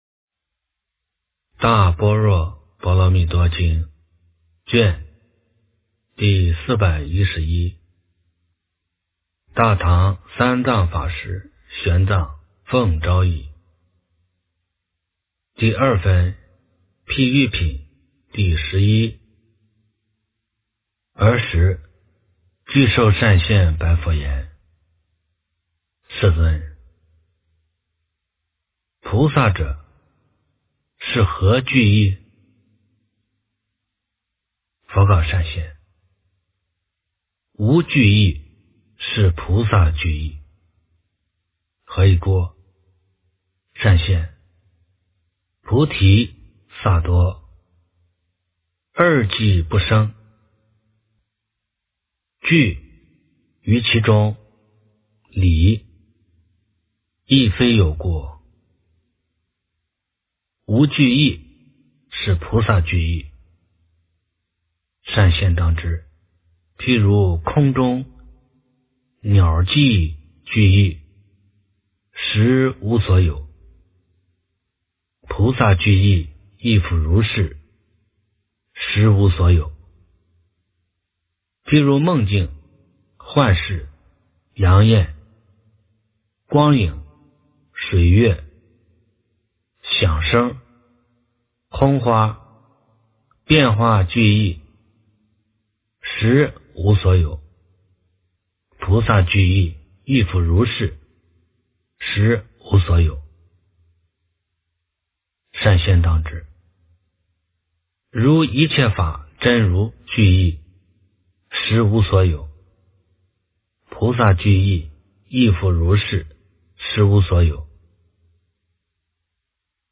大般若波罗蜜多经第411卷 - 诵经 - 云佛论坛